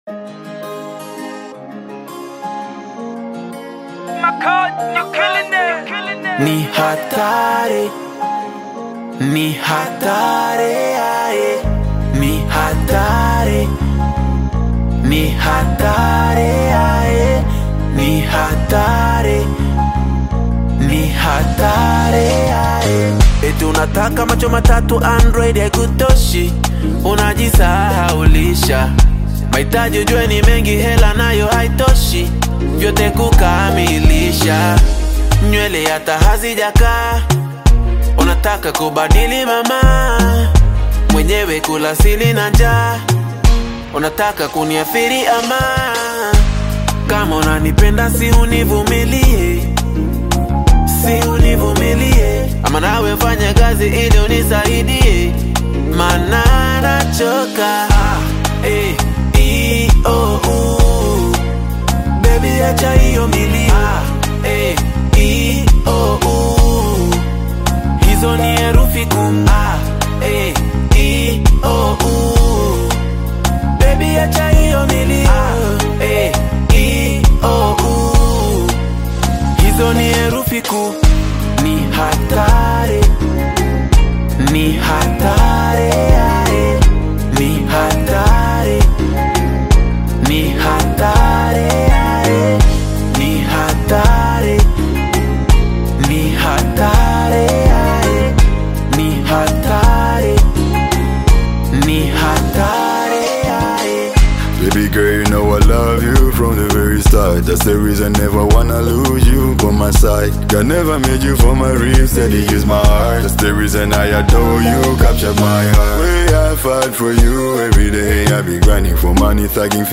is a high-energy anthem
even when moving at a faster tempo
A seamless blend of Rap